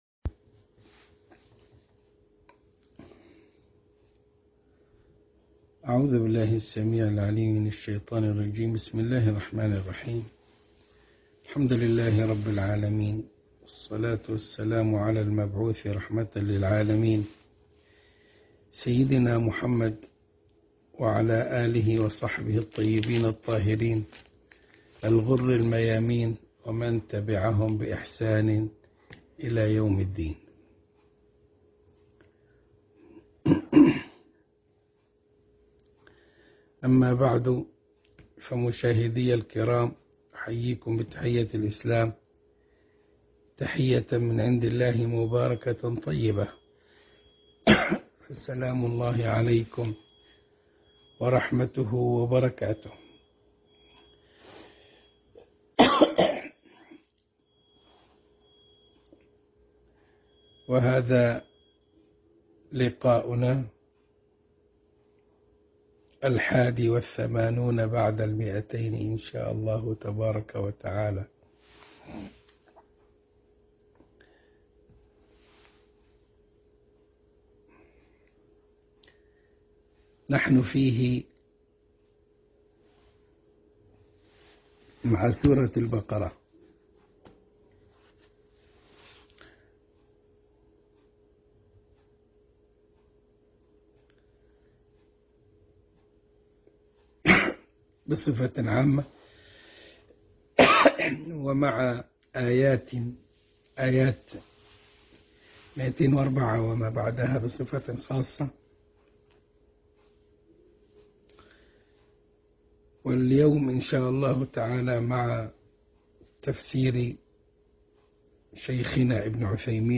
الدرس ٢٨١ من الإبريز في تفسير الكتاب العزيز سورة البقرة الآية ٢٠٤وما بعدها